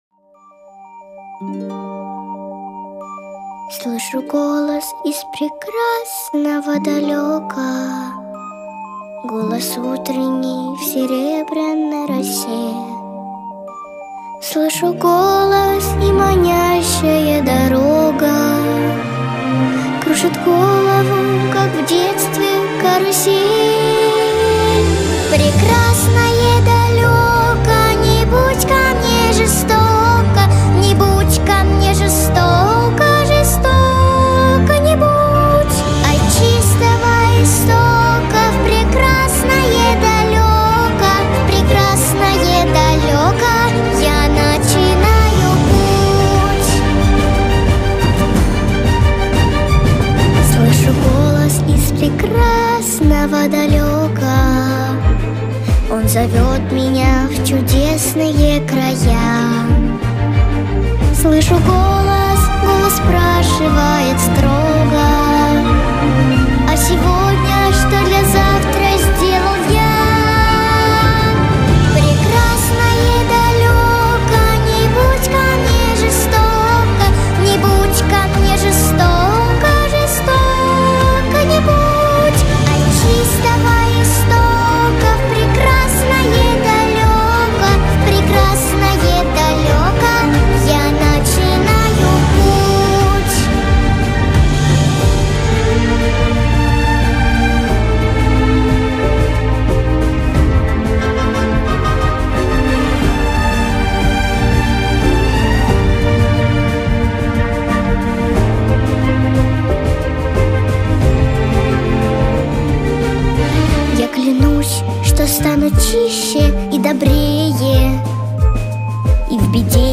девушка поёт